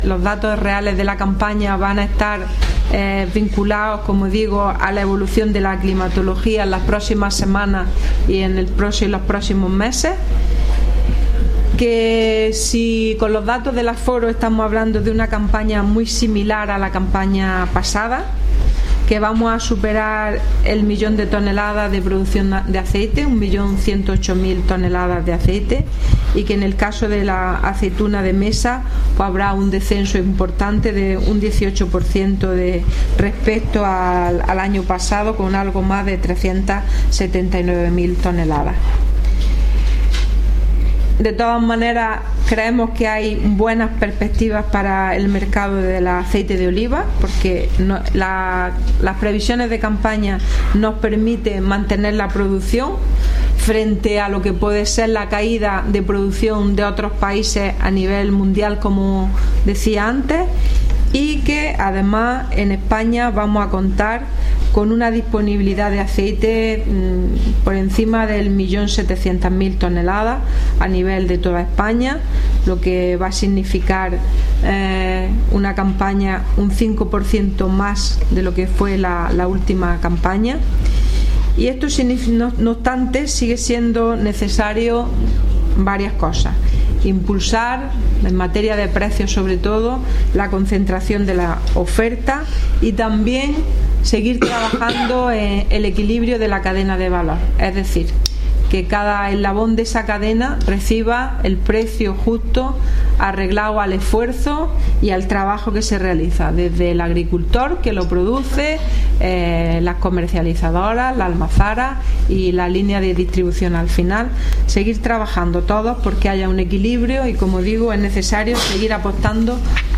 Declaraciones de Carmen Ortiz sobre primer aforo de producción del olivar en Andalucía en la campaña 2016-2017